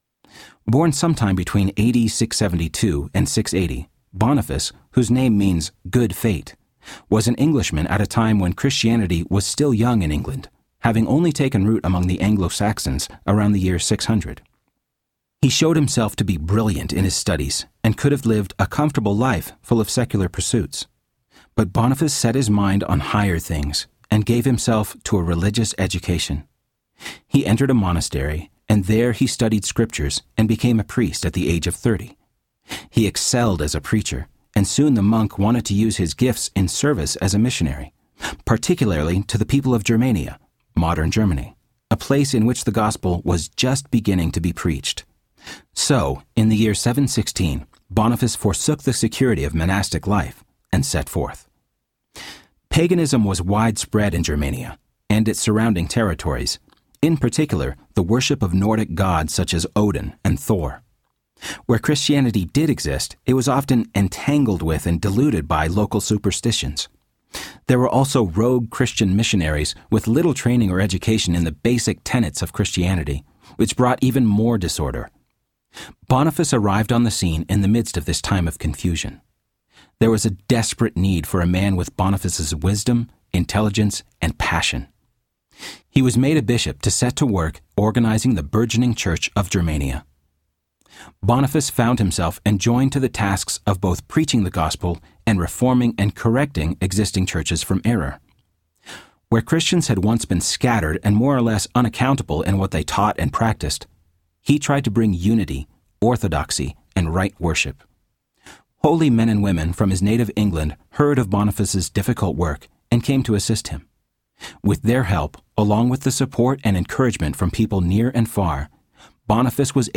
Bearded Gospel Men Audiobook
5.92 Hrs. – Unabridged